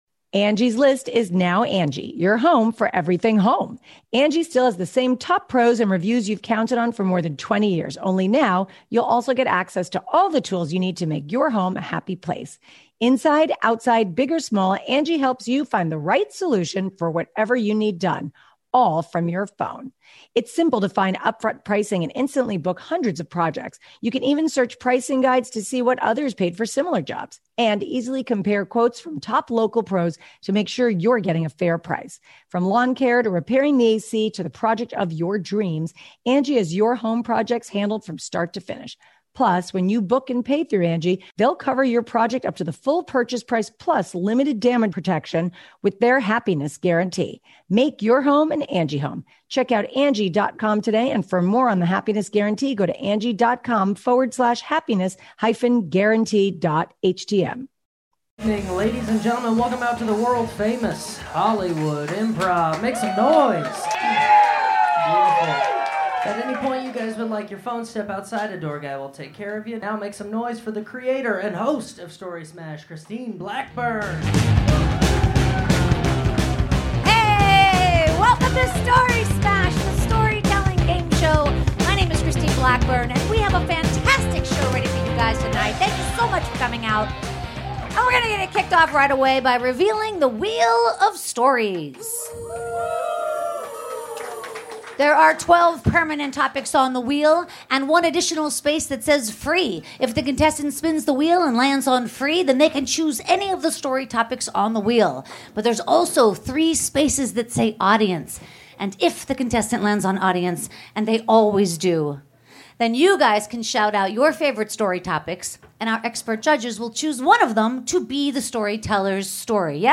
590 - Story Smash the Storytelling Gameshow LIVE at The Hollywood Improv!
Contestants spin the Wheel of Stories and tell a true, 1, 2 or 3 minute story on whatever topic they land on.